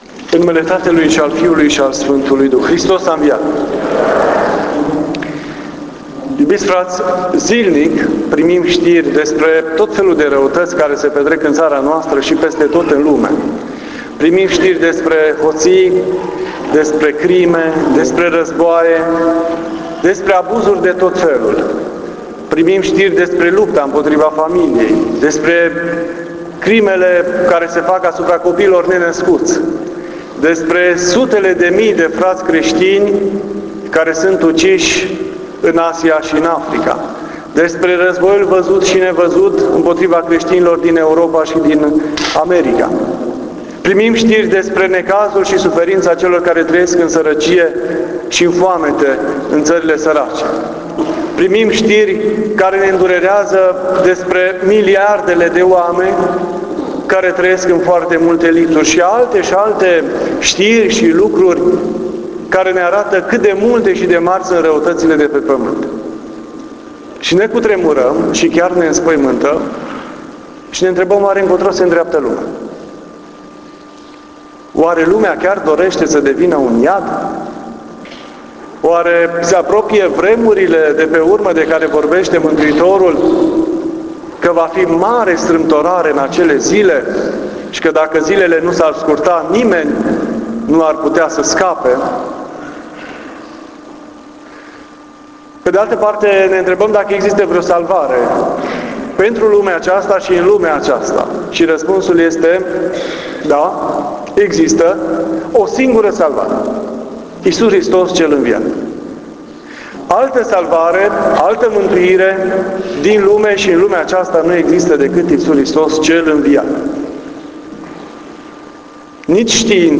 Iisus Hristos Cel înviat salvarea lumii” (17.04.2017) Posted on April 17, 2017 April 17, 2017 admin Posted in predici Cuvânt la Învierea Domnului.